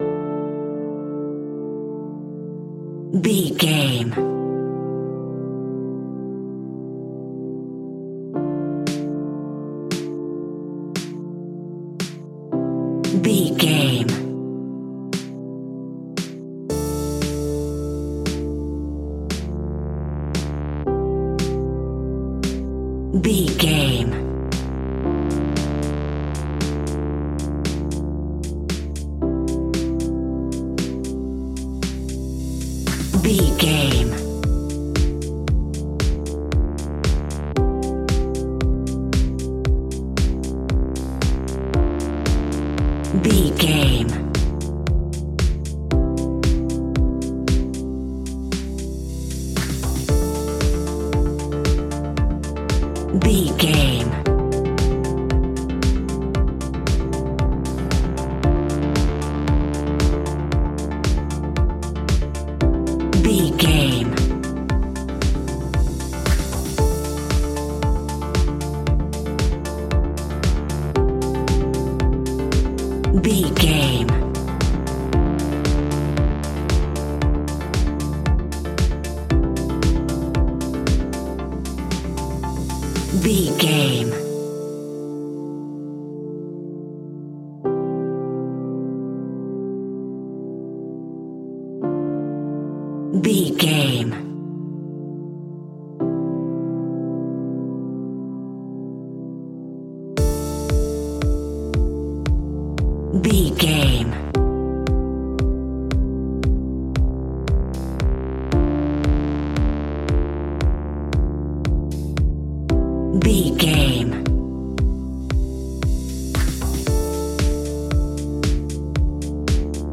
Aeolian/Minor
D
funky
groovy
uplifting
driving
energetic
drum machine
electric piano
synthesiser
electro house
funky house
synth bass